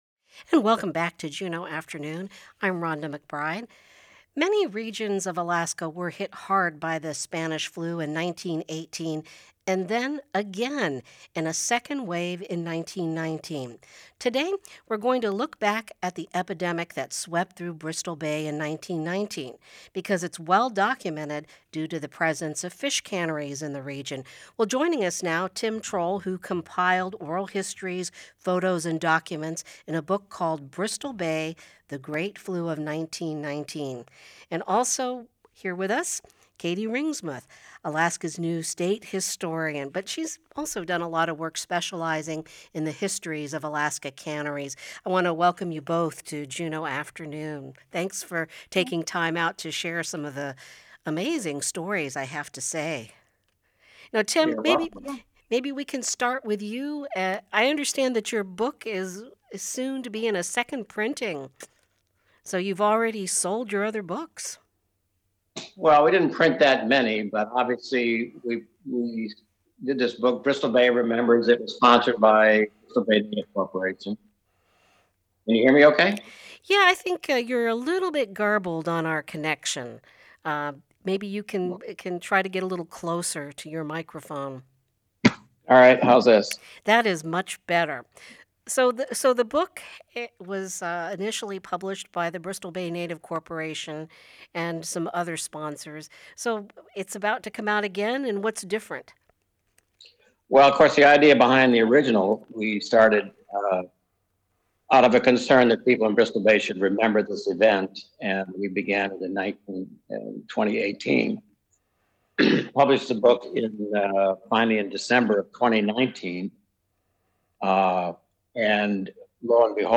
In the first part of the program, we hear from two historians